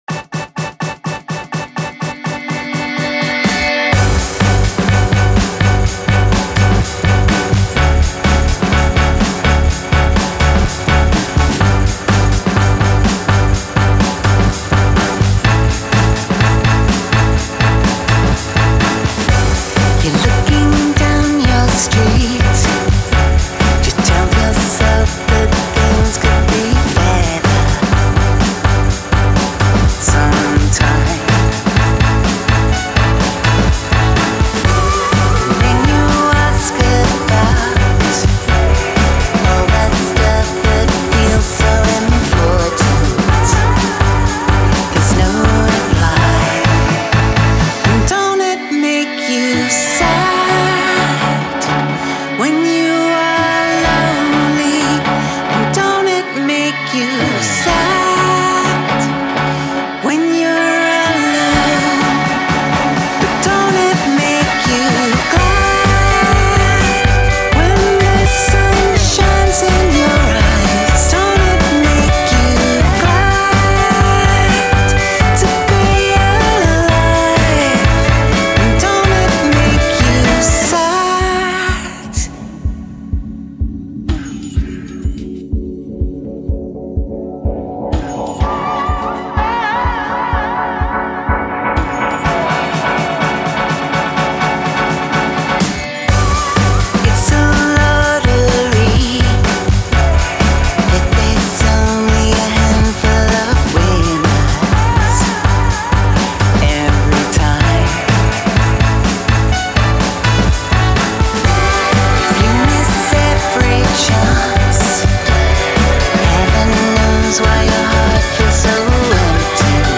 поп музыка
поп-трио